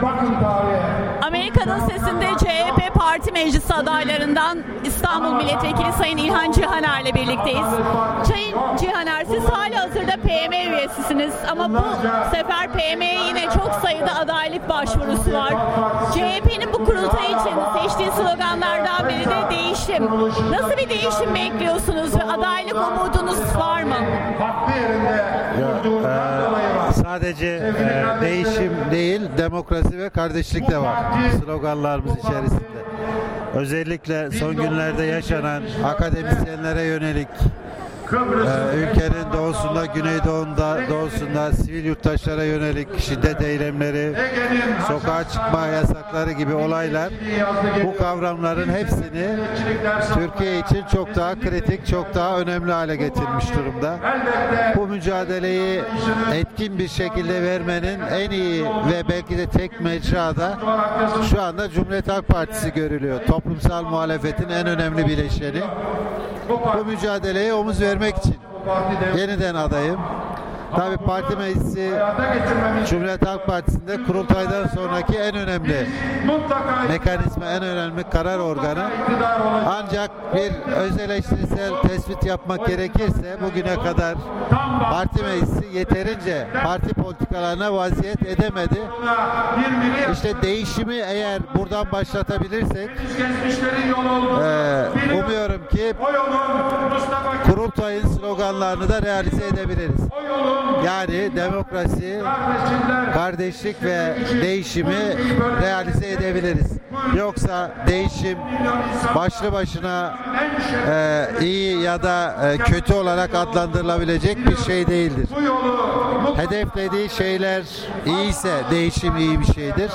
İlhan Cihaner ile söyleşi